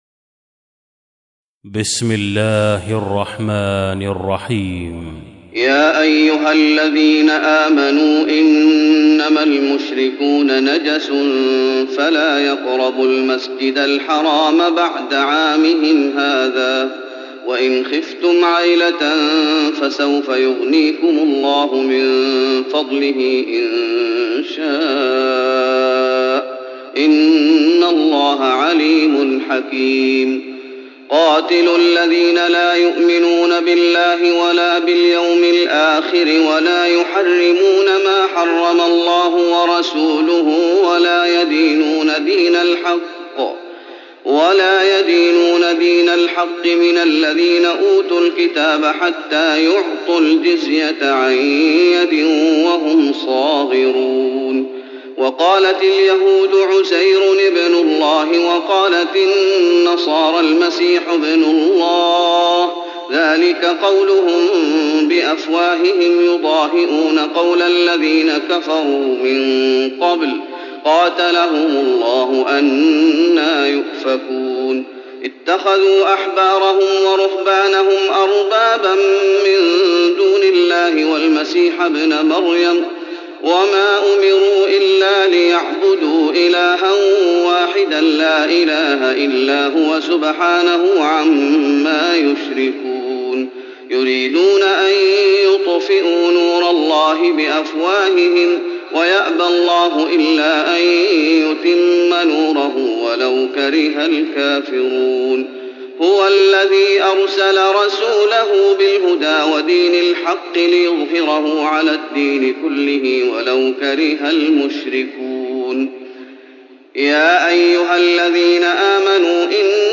تراويح رمضان 1415هـ من سورة التوبة (28-99) Taraweeh Ramadan 1415H from Surah At-Tawba > تراويح الشيخ محمد أيوب بالنبوي 1415 🕌 > التراويح - تلاوات الحرمين